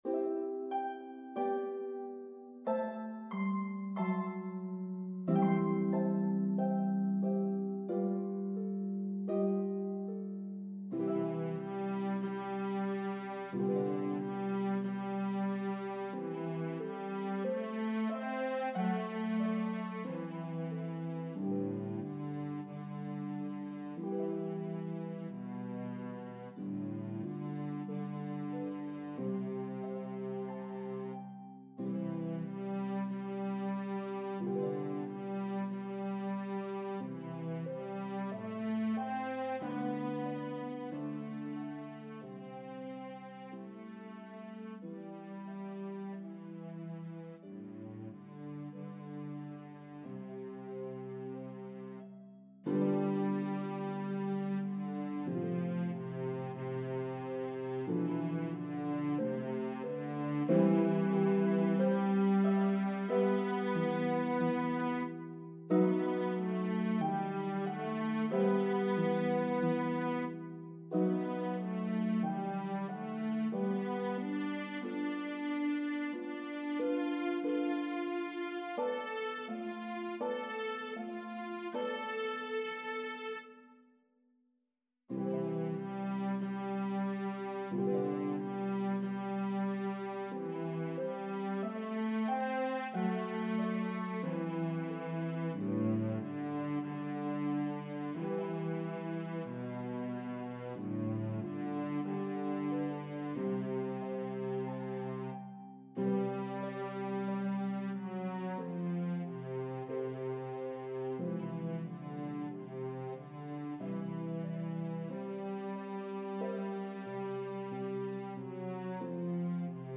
The elegant, famous melody will enchant your audiences!
Harp and Cello version